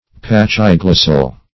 Search Result for " pachyglossal" : The Collaborative International Dictionary of English v.0.48: Pachyglossal \Pach`y*glos"sal\ (p[a^]k`[i^]*gl[o^]s"sal), a. [Pachy- + Gr. glw^ssa tongue.]